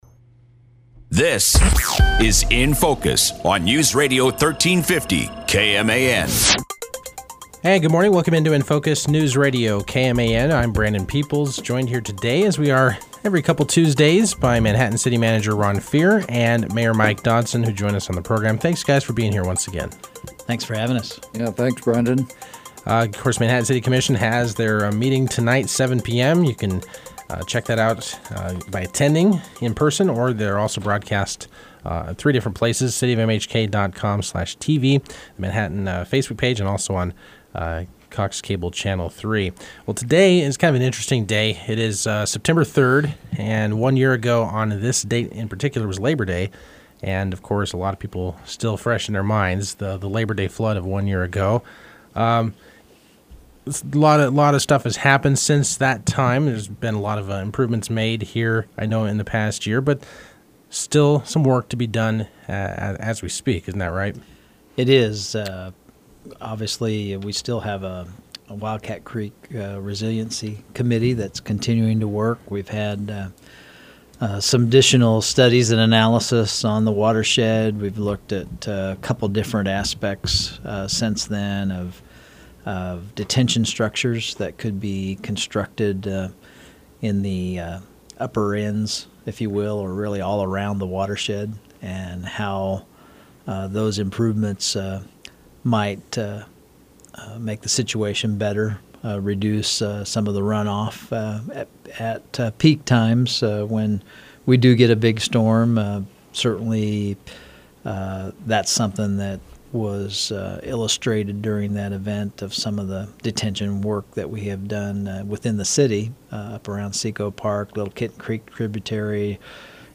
Manhattan City Manager Ron Fehr and Mayor Mike Dodson previewed Tuesday’s City Commission meeting and discussed the one year anniversary of the 2018 Labor Day flood.